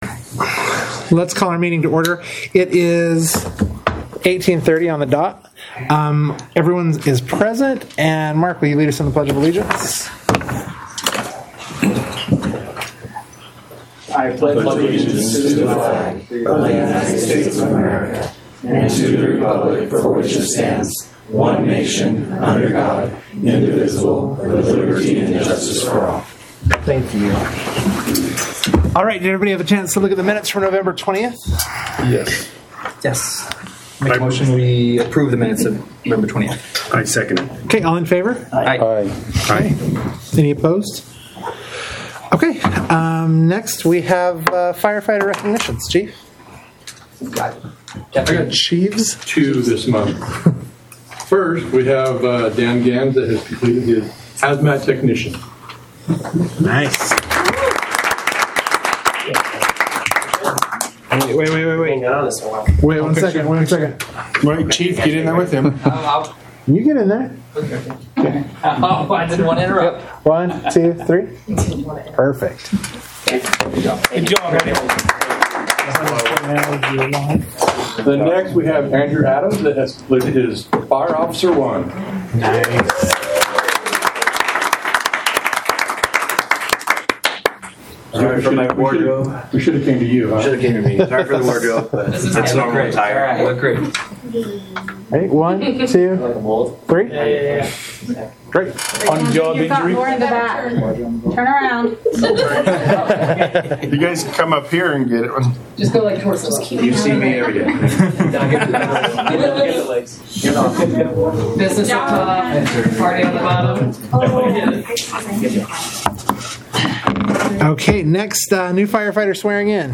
Board Meeting
The North Tooele Fire Protection Service District will hold a board meeting on December 18, 2024 at 6:30 p.m. at the Stansbury Park Fire Station, 179 Country Club, Stansbury Park, UT, 84074.